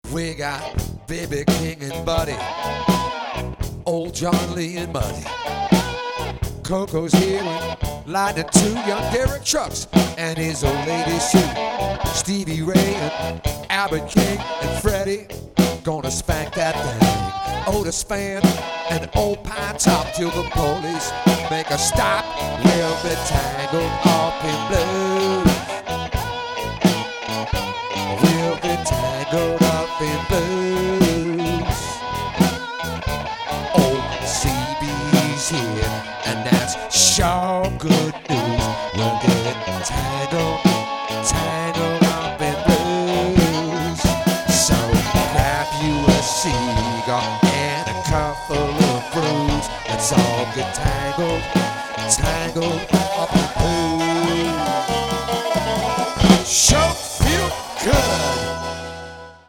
We are your audio home for the best blues anyone can servce up.